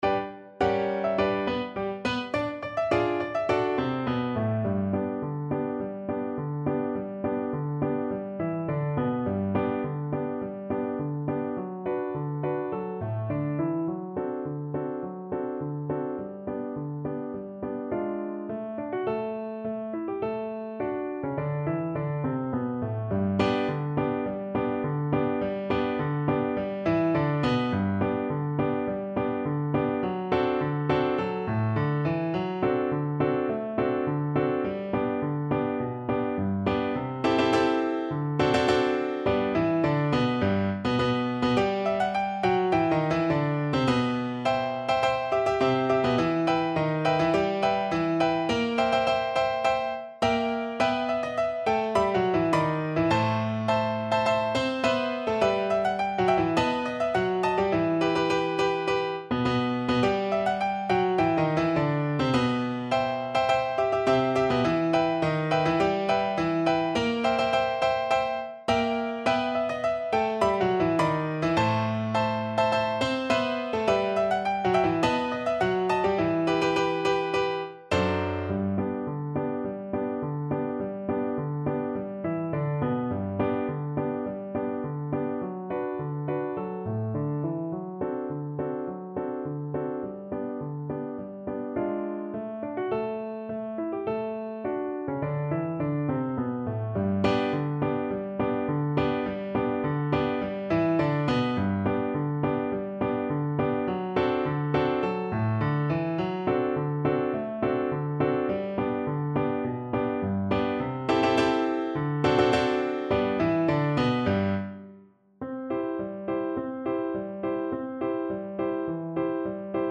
Quick March = c.104